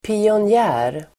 Ladda ner uttalet
Uttal: [pionj'ä:r]